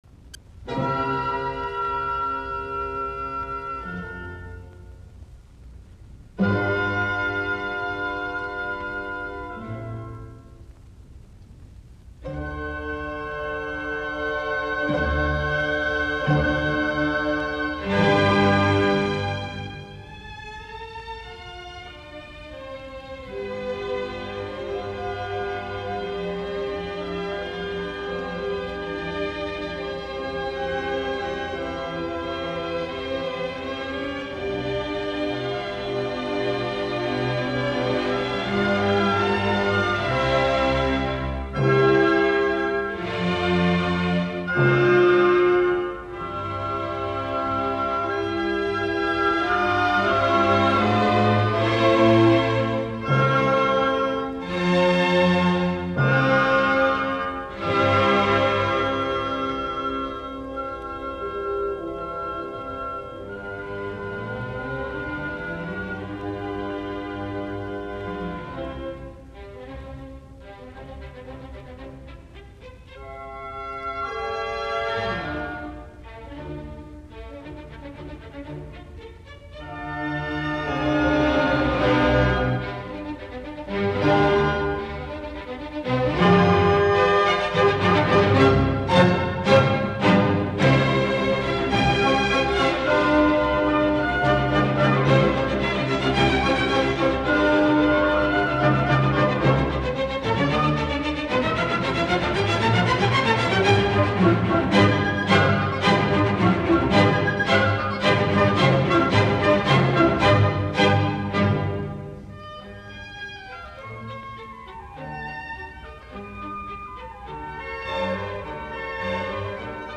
r1952, Carnegie Hall, New York.